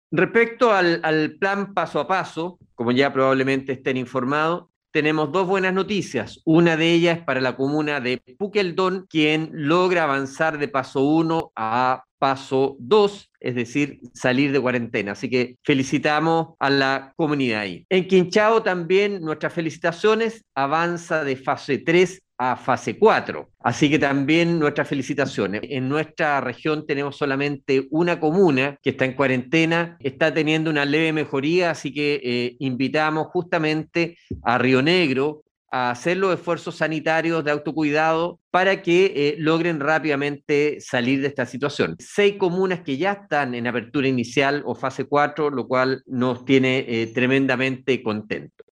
Alejandro Caroca se refirió también a los cambios en el Plan Paso a Paso en la región: